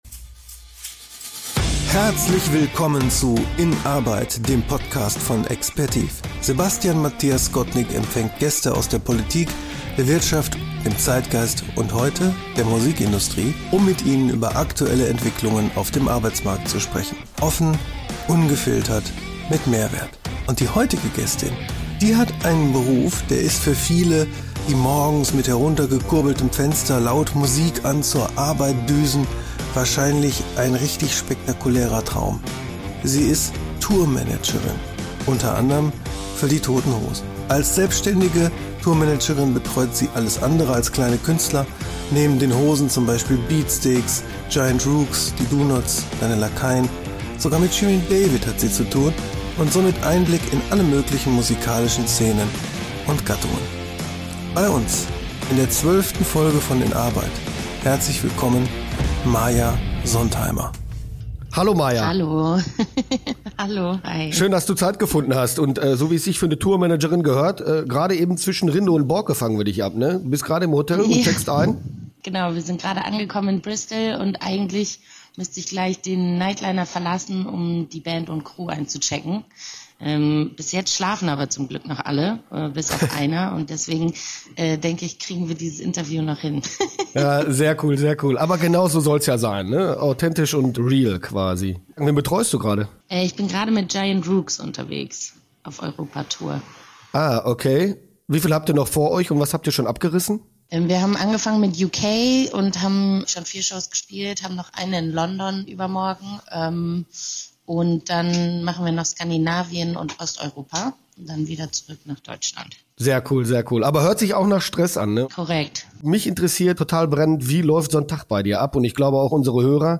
Ein Gespräch über die Abläufe auf und hinter der Bühne, die Realität der Musikwelt heute und die Ruhe an der frischen Luft.